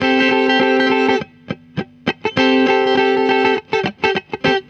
TR GTR 2.wav